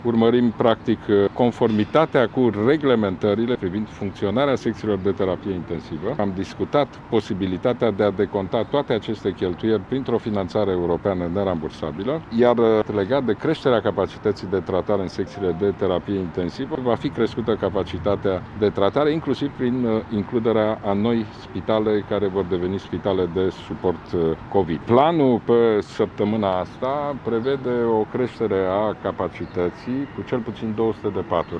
Prim ministrul a spus că va fi elaborat un plan de conformitate care va fi prezentat manegerilor de spitale: